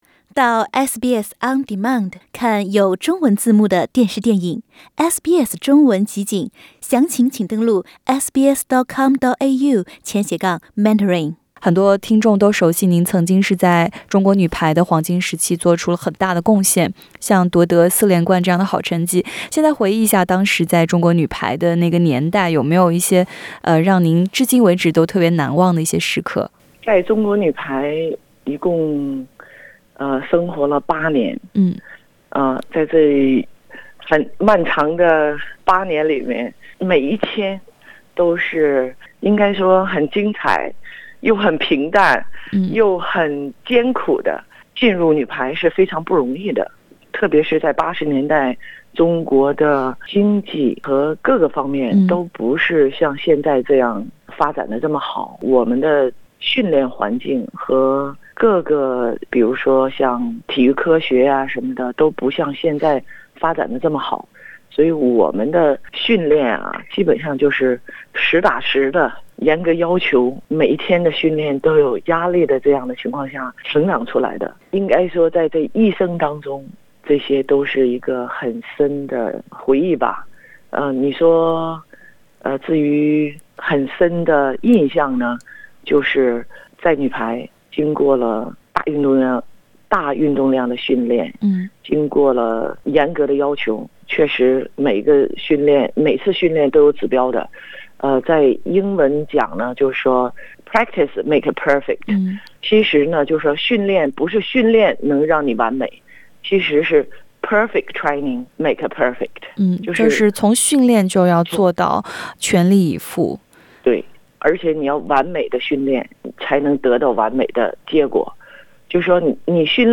姜英专访：走过中国女排的”光荣岁月”【我们的故事】